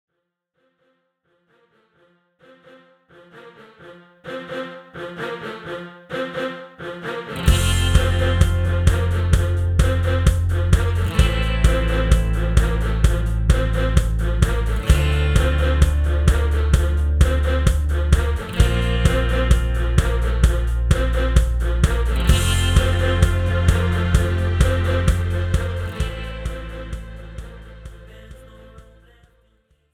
Categories Pop , R&B , Rock